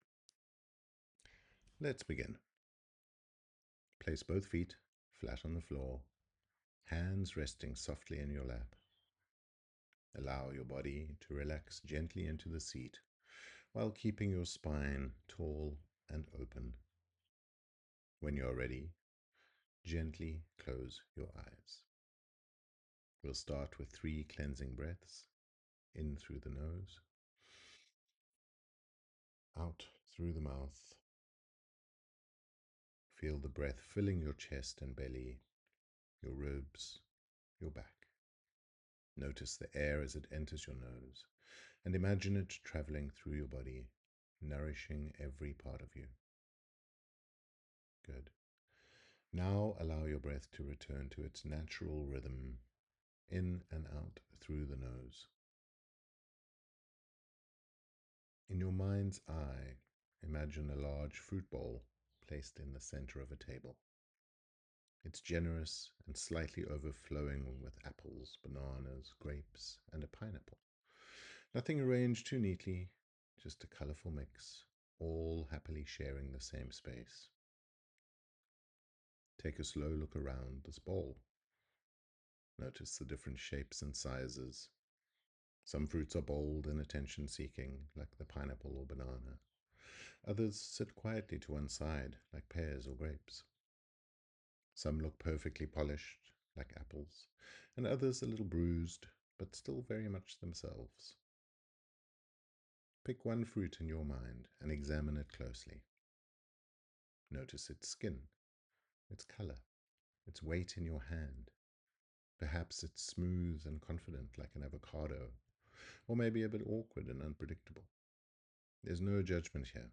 Marriage Memos Meditation
HP02-meditation.mp3